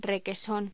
Locución: Requesón
locución
Sonidos: Voz humana